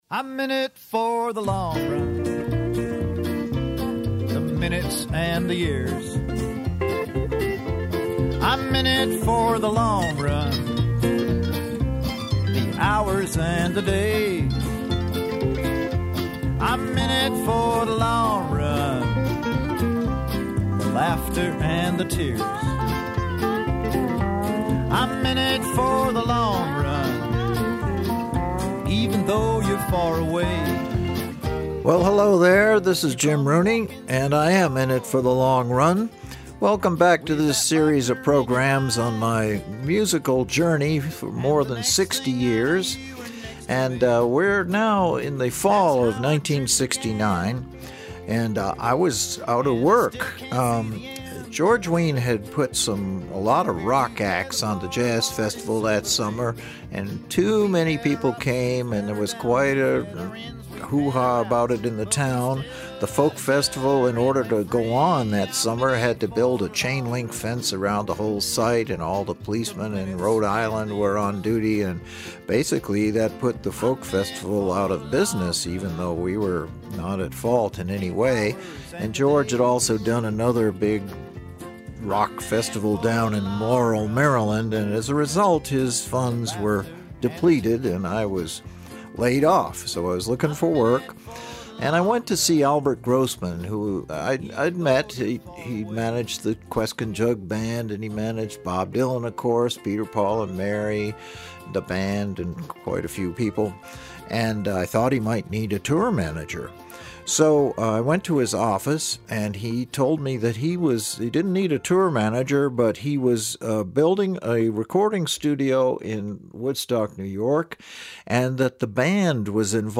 Feel free to “Like” it These podcasts are installments broadcast by Royalton Community Radio in Vermont, a sort of companion soundtrack to Jim’s new memoir IN IT FOR THE LONG RUN , his third book about the history of this music.